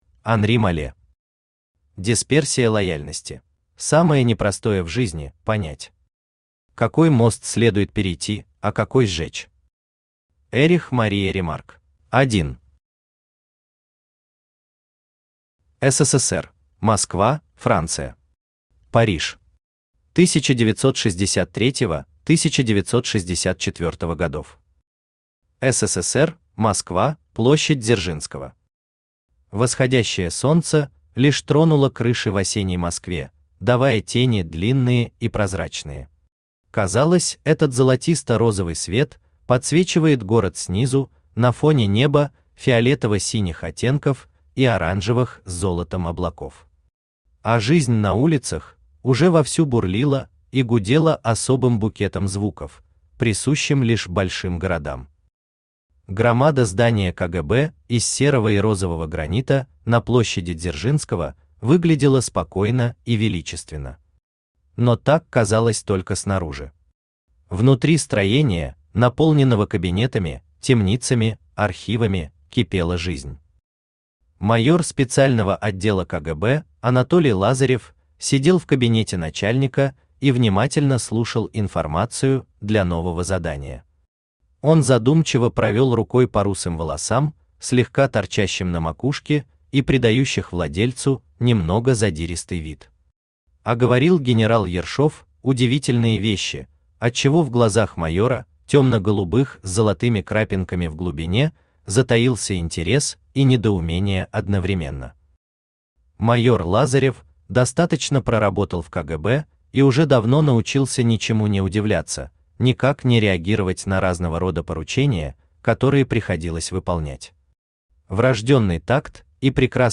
Аудиокнига Дисперсия лояльности | Библиотека аудиокниг
Aудиокнига Дисперсия лояльности Автор Анри Малле Читает аудиокнигу Авточтец ЛитРес.